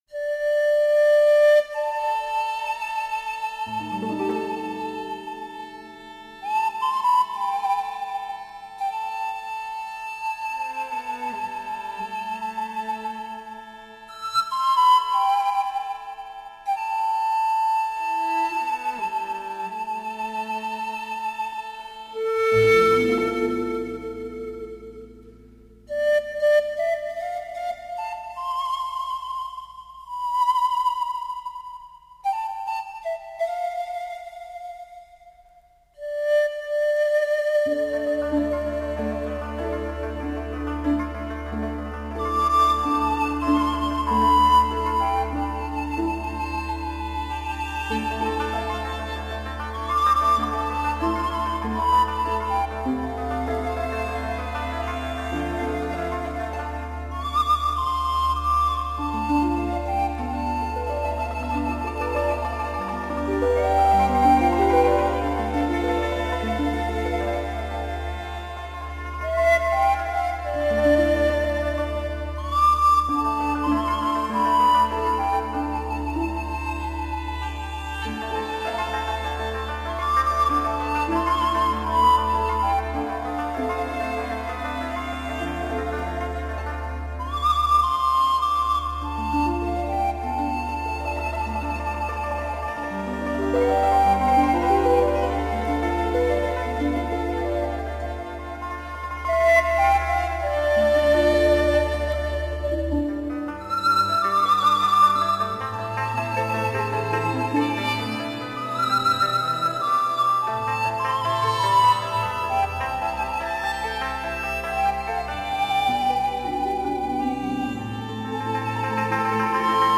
音乐类型: 民乐